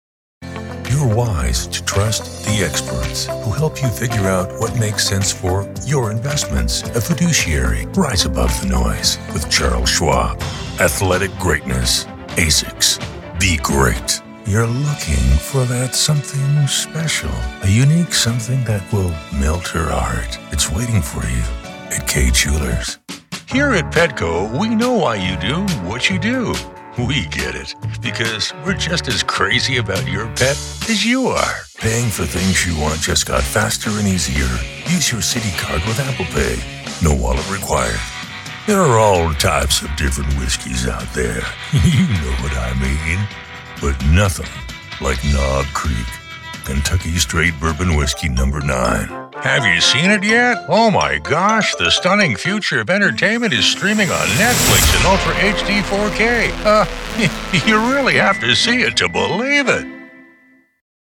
Hear the upbeat voices used in Capital FM promotional content.